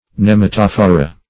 Search Result for " nematophora" : The Collaborative International Dictionary of English v.0.48: Nematophora \Nem`a*toph"o*ra\, n. pl.